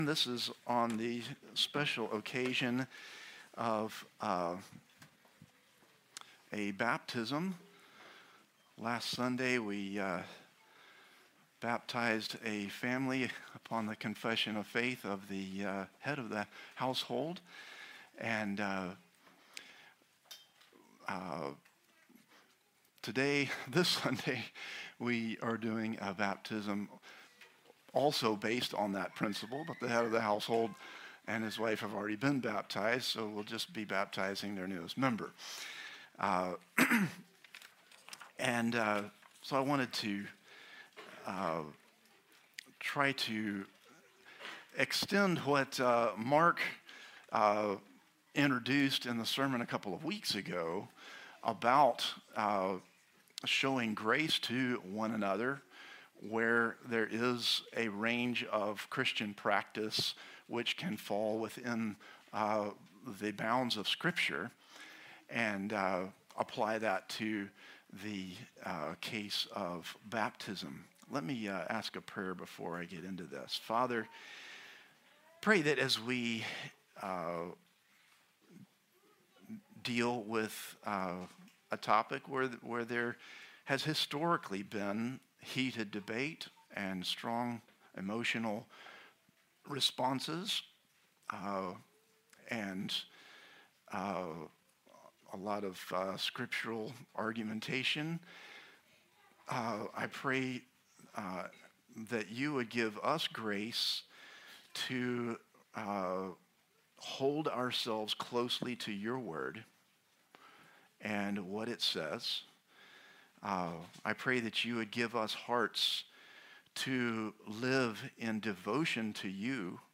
Christ the Redeemer Church | Sermon Categories Matthew